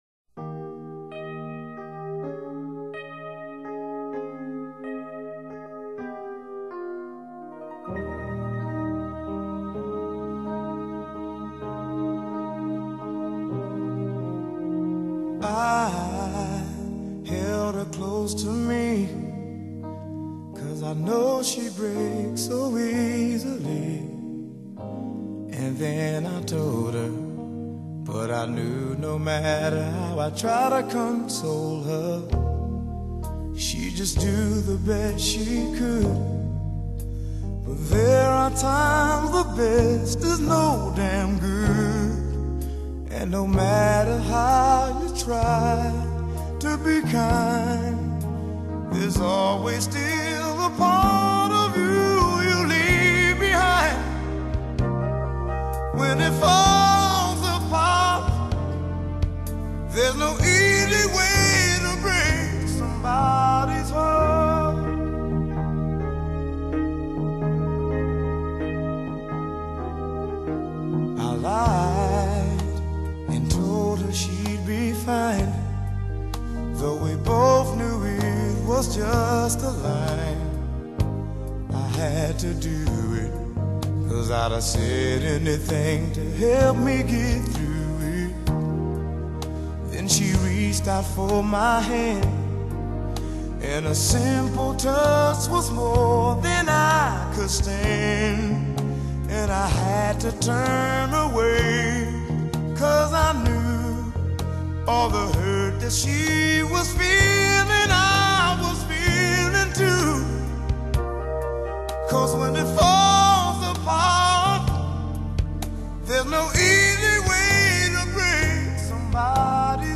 Genre: Funk,Soul